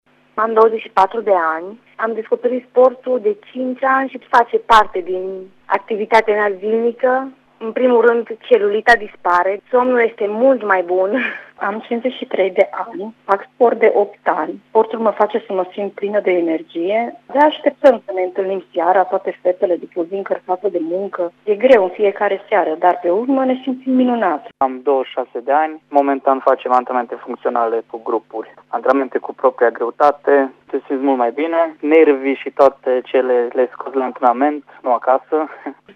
Clienții care rămân fideli antrenamentelor sportive spun că mișcarea îți aduce numeroase beneficii: